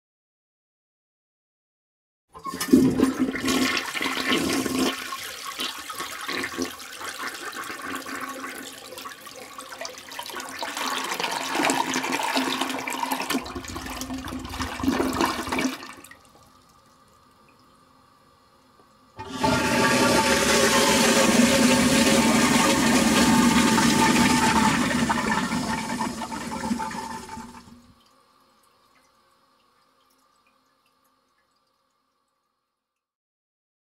دانلود صدای سیفون دست شویی از ساعد نیوز با لینک مستقیم و کیفیت بالا
جلوه های صوتی